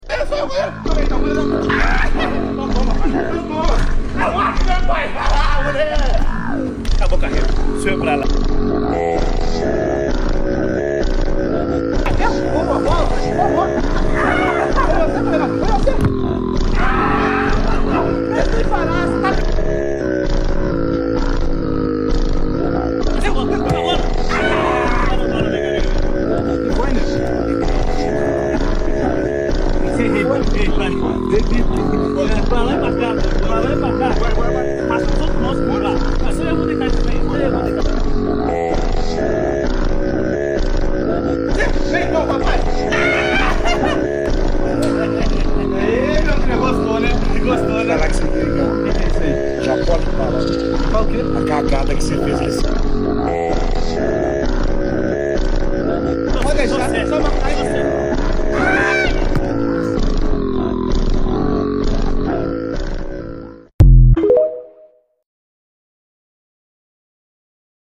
wow sound effects free download